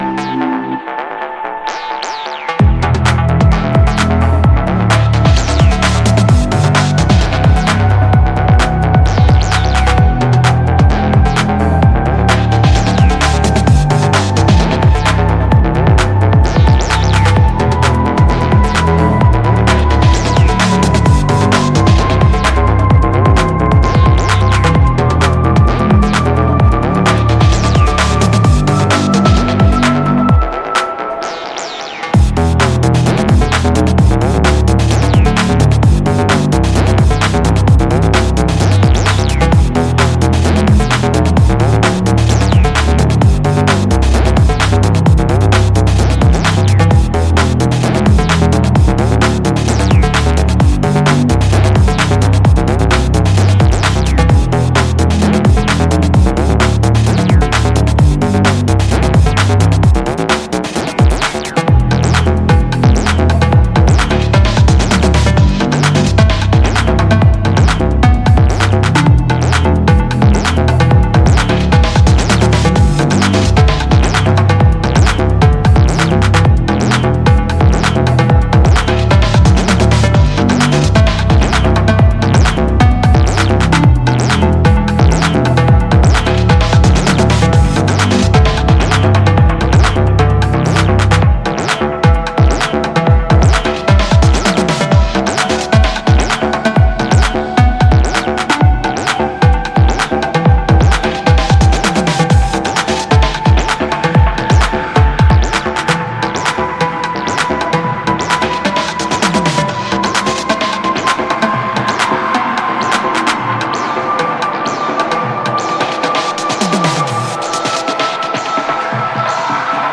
breaks n deep acid ravers.
Breaks , Electro